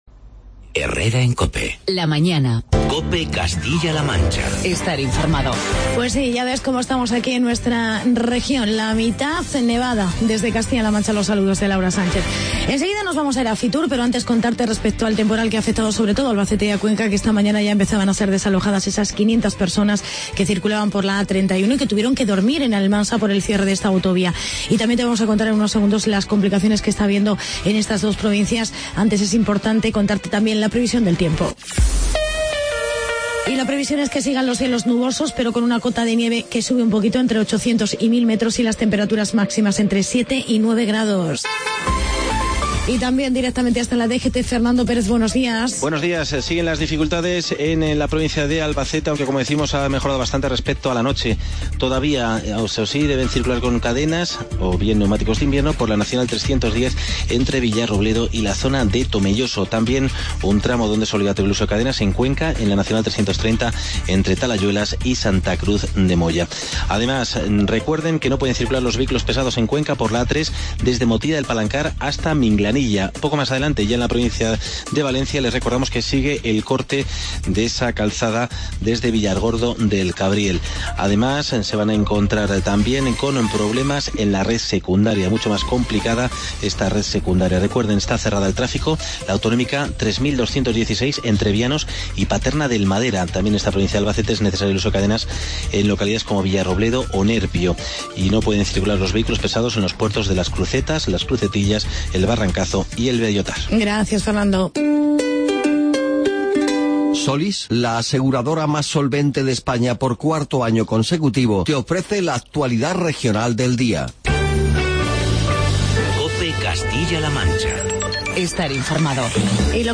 Especial Fitur. Entrevista con el vicepresidente del gobierno de CLM.